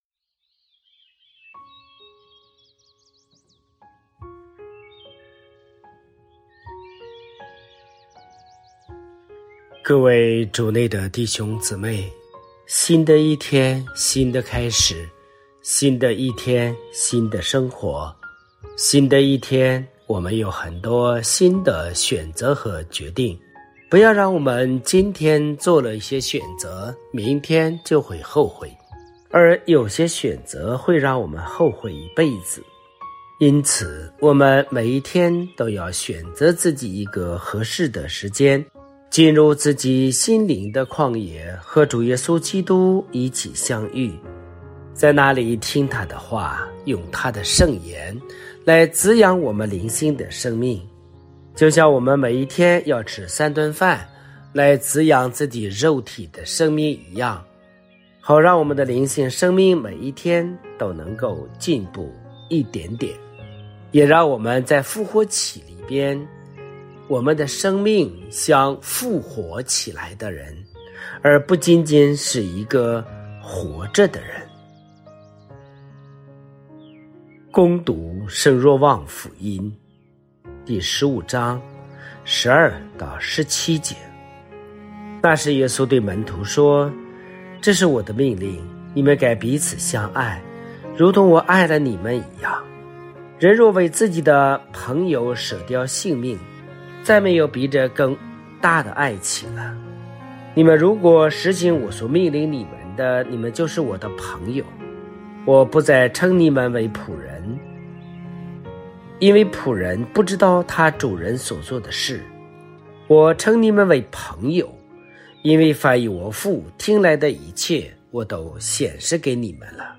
坚持每天聆听神父的道理！